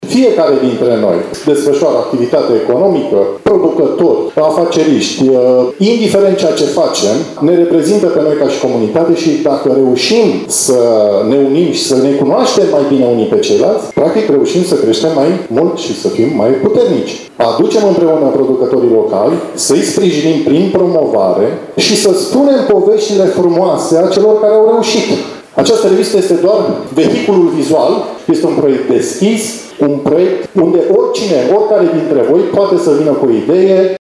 Astăzi a fost lansat, în cadrul unui eveniment care a avut loc la Camera de Comerț și Industrie Suceava, cel de-al 6 –lea număr al revistei Pentru Localnici.